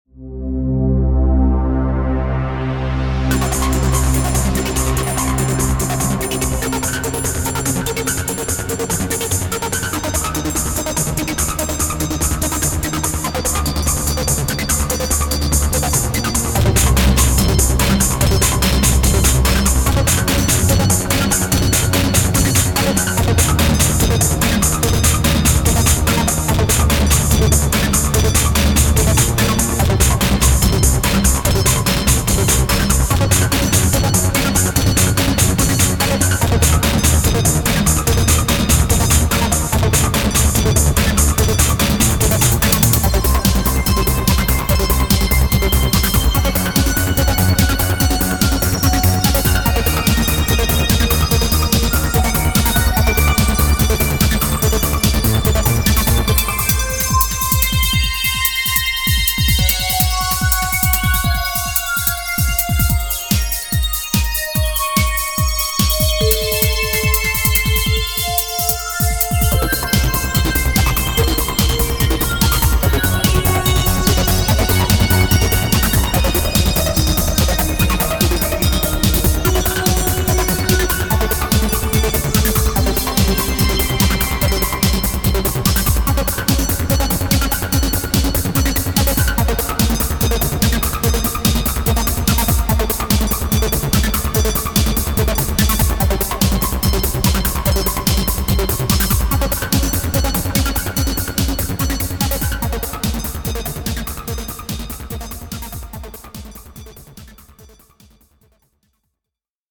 Electro Industrial
145bpm